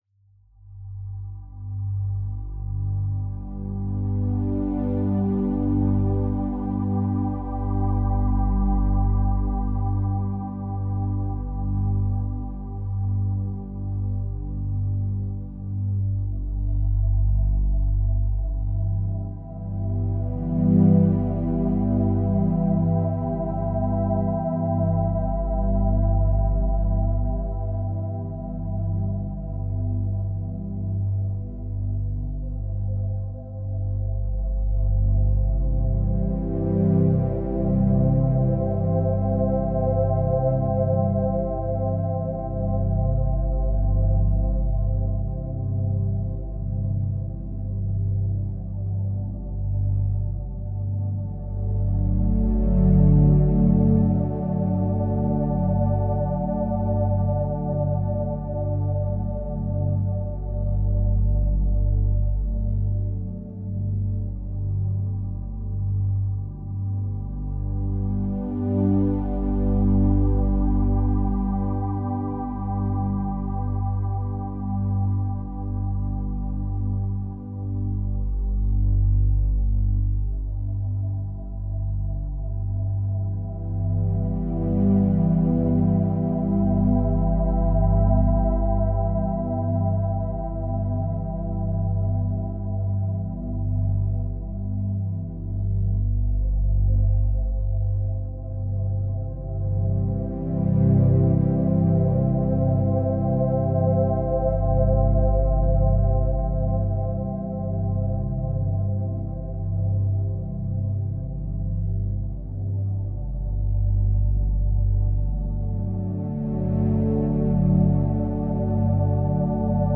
8hz - Theta Binaural Beats for Intuition ~ Binaural Beats Meditation for Sleep Podcast
Mindfulness and sound healing — woven into every frequency.